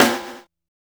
snare03.wav